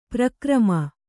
♪ prakrama